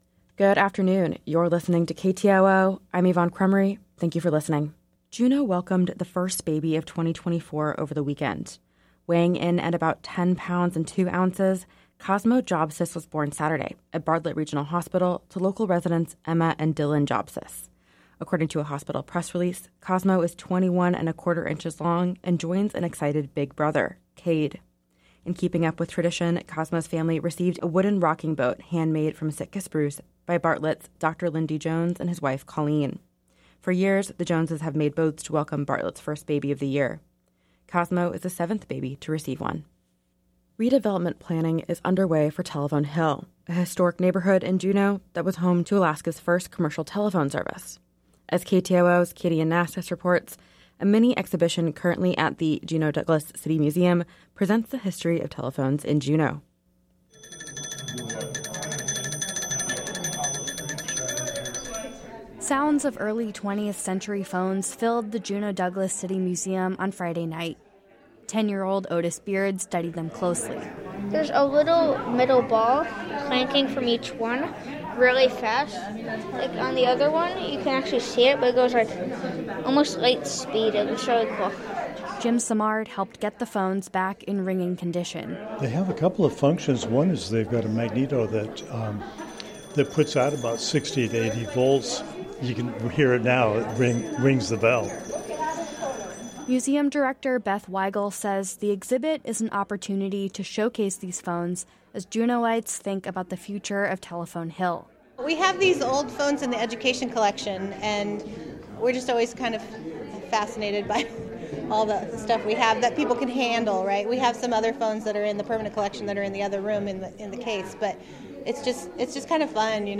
Newscast – Monday. Jan. 8 2024